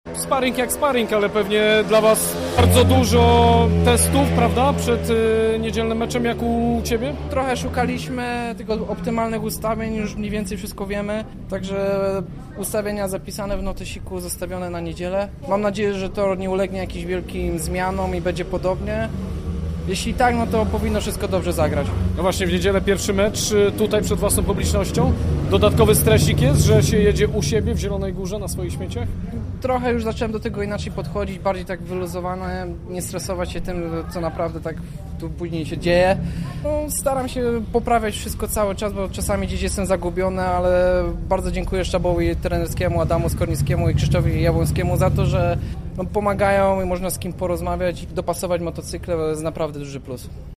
Pomeczowe wypowiedzi